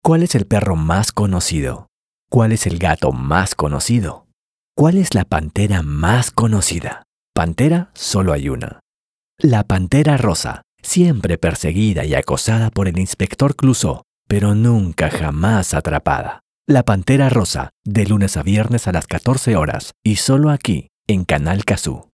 Hörbücher
Ich habe mein eigenes Studio.